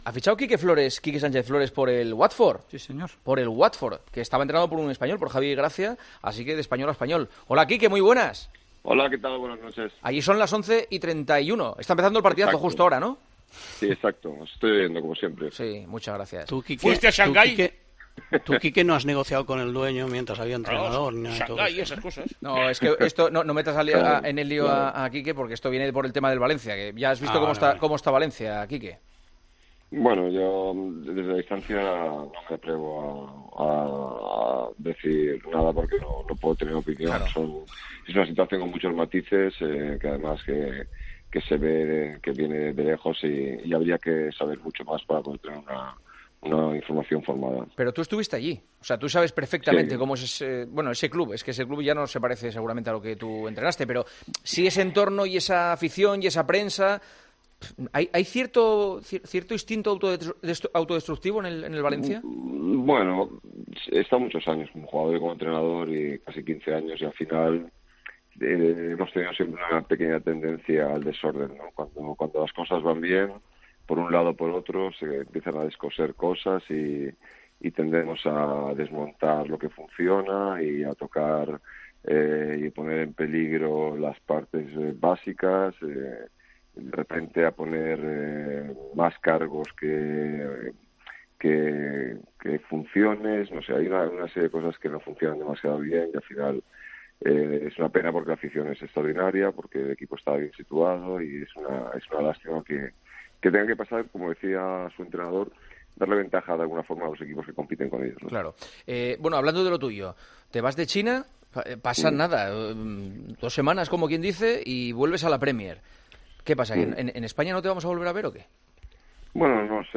Entrevistas en El Partidazo de COPE